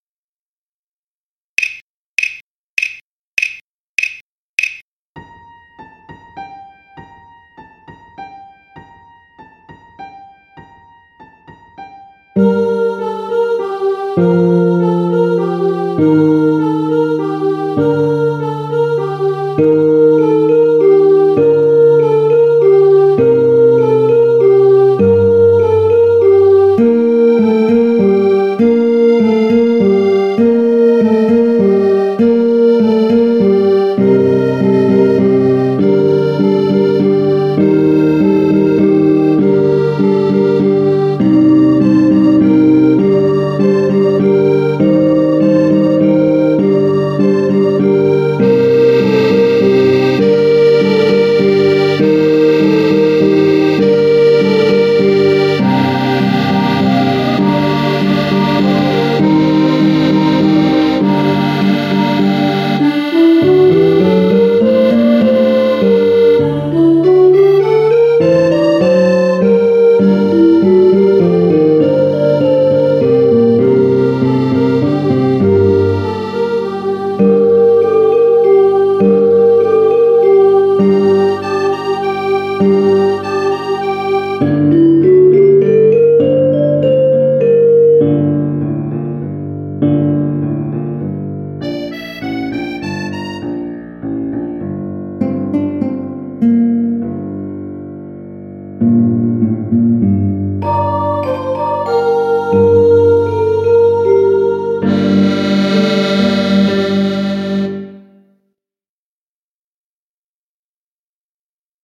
ensemble :
carol-of-the-bells-ensemble.mp3